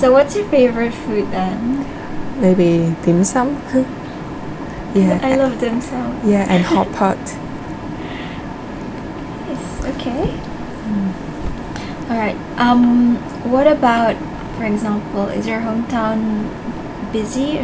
S1 = Brunei female S2 = Chinese female Context: S2 has just said that one of the reasons she loves Guangzhou is because the food there is excellent.
Intended Words: hotpot Heard as: hot plate Discussion: S1 does not know the term hotpot - a Chinese way of eating where the food is cooked in a pot of boiling water on the table.